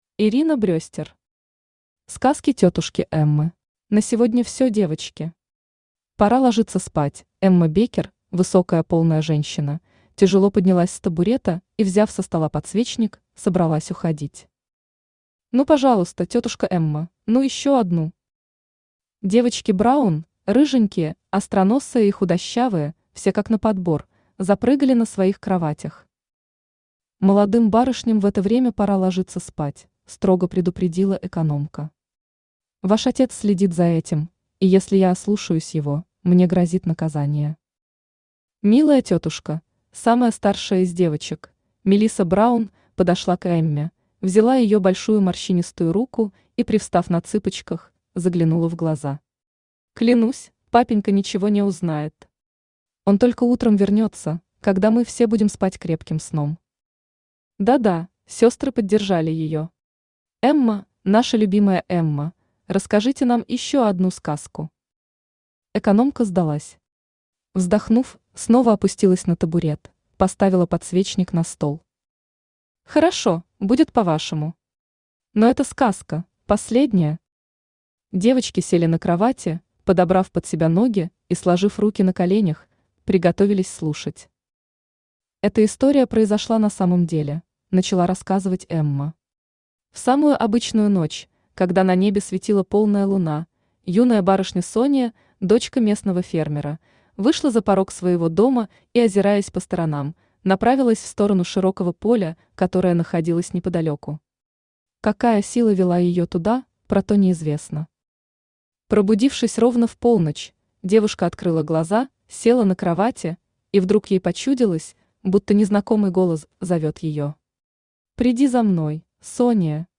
Аудиокнига Сказки тётушки Эммы | Библиотека аудиокниг
Aудиокнига Сказки тётушки Эммы Автор Ирина Брестер Читает аудиокнигу Авточтец ЛитРес.